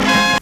HORN BURST-R.wav